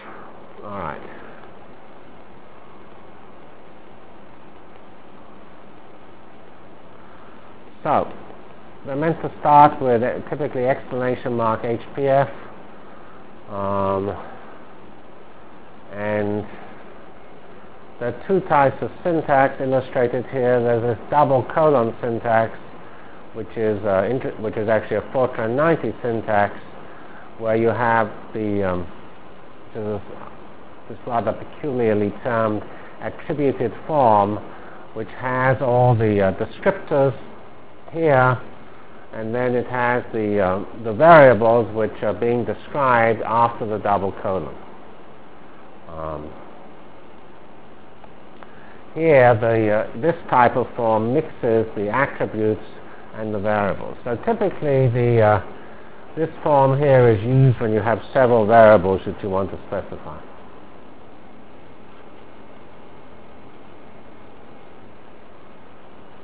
CPS615-Introduction to Virtual Programming Lab -- Problem Architecture Continued and Start of Real HPF Delivered Lectures of CPS615 Basic Simulation Track for Computational Science